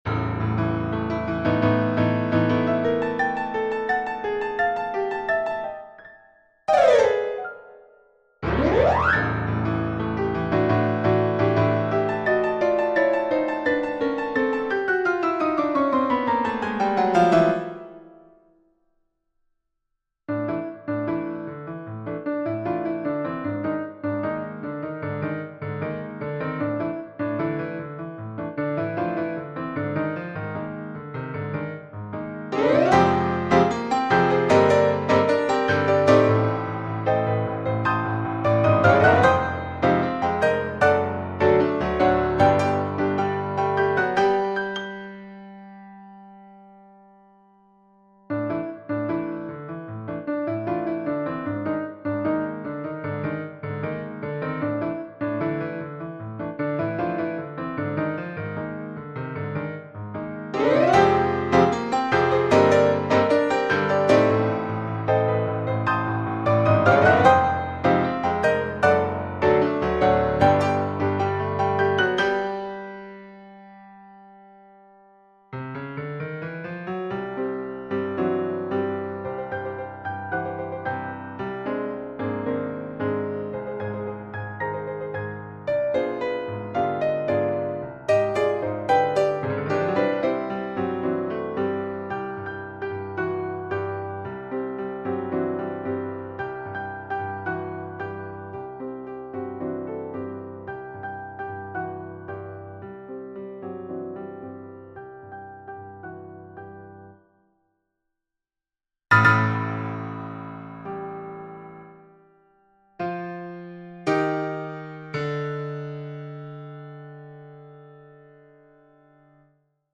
SAB mixed choir and piano
世俗音樂